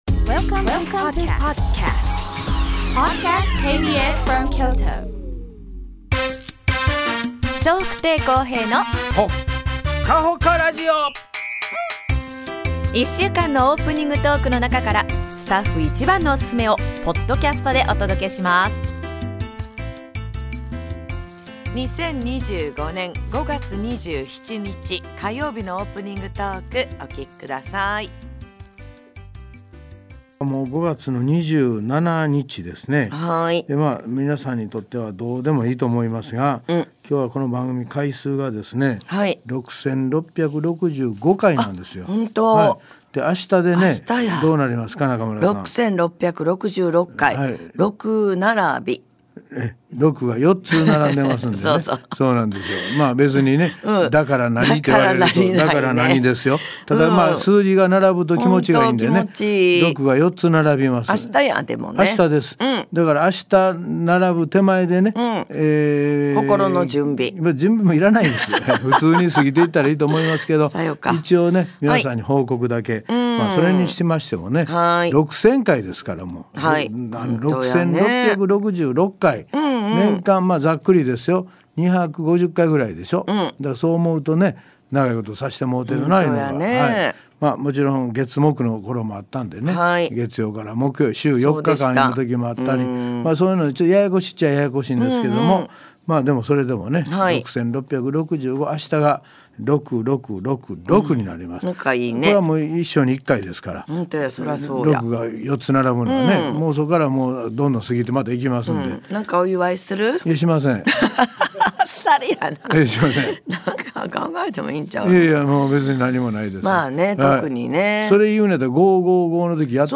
Tidak perlu mendaftar atau memasang. 2024年12月30日～2025年1月3日のオープニングトーク. 2024年12月26日のオープニングトーク.